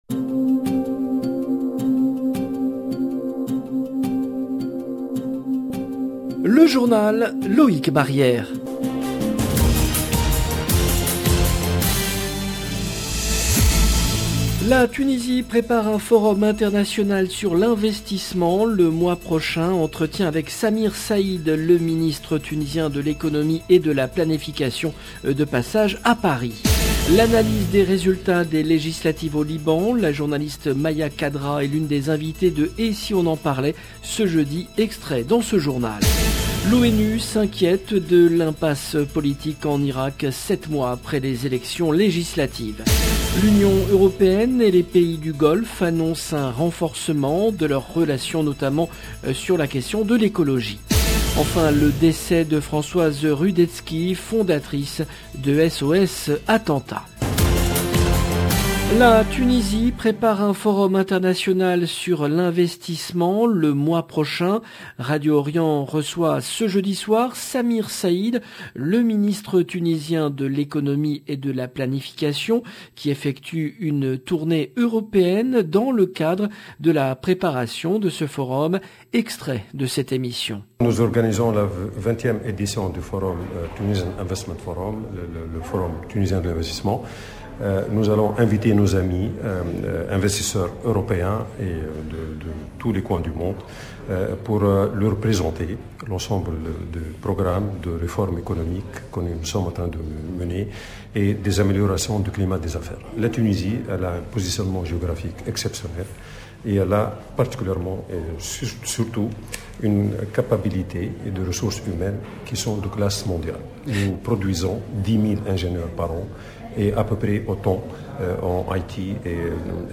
JOURNAL EN LANGUE FRANÇAISE
La Tunisie prépare un forum international sur l'investissement le mois prochain. Entretien avec Samir Saïd, le ministre tunisien de l’Economie et de la planification de passage à Paris.